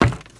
STEPS Wood, Creaky, Run 30.wav